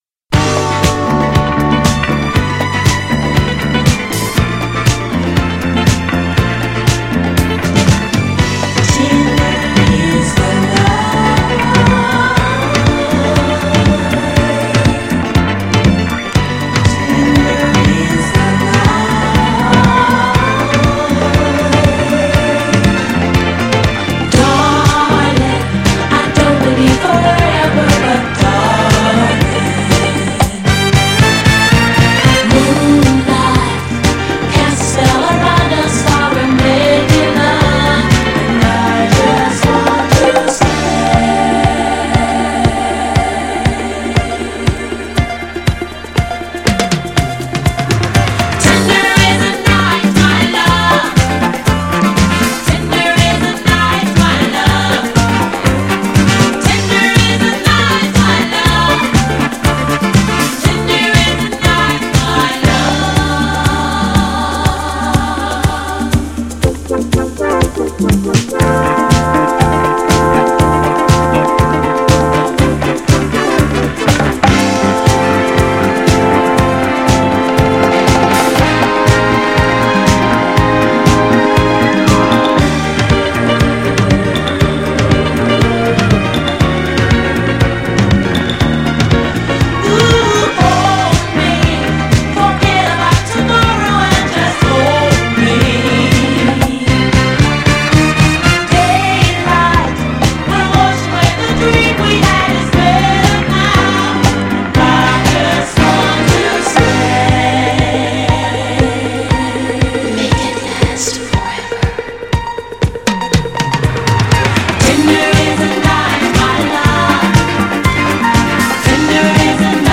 ヨーロピアンオーケストラDISCOサウンド!!
GENRE Dance Classic
BPM 116〜120BPM
アップリフティング # オーケストラ # ストリングス # ドラムブレイク # 妖艶